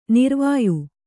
♪ nirvāyu